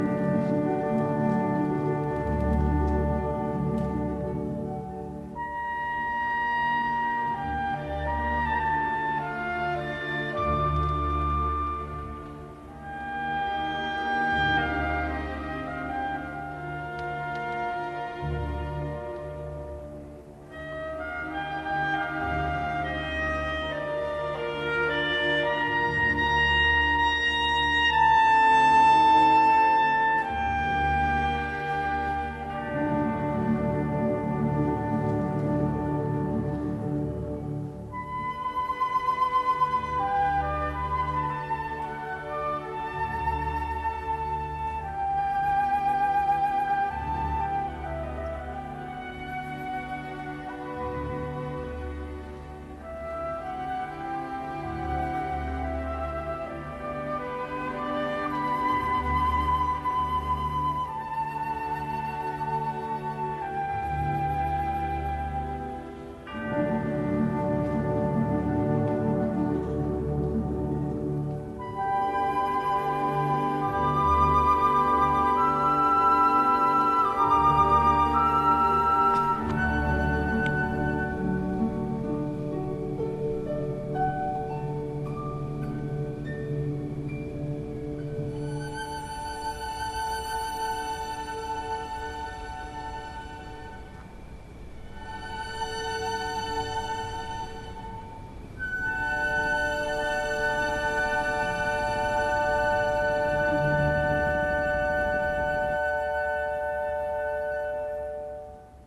With a nod to the plentiful rainfall and mild summers of the Pacific Northwest, a lush and extensive forest features a rich botanical landscape with wildlife and birds throughout. (Turn off the stream and listen to the ambient sound:)